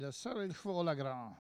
Langue Maraîchin
locutions vernaculaires
Catégorie Locution